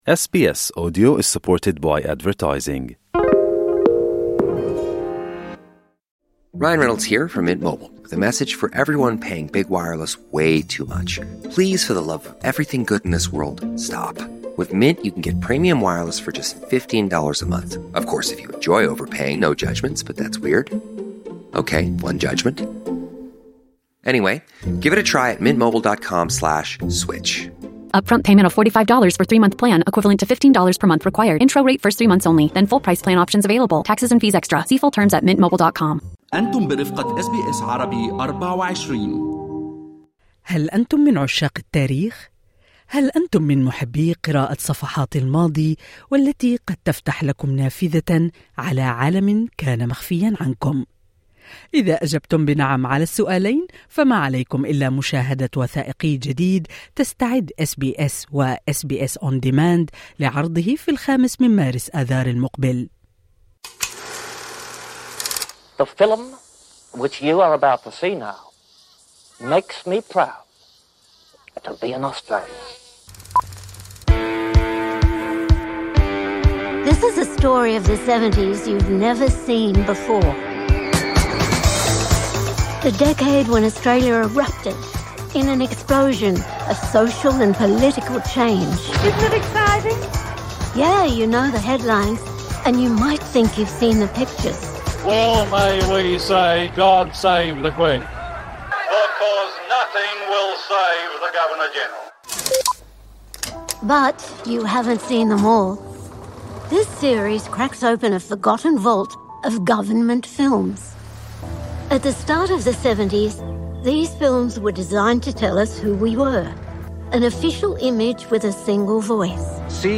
في حديث لميكرفون اس بي اس عربي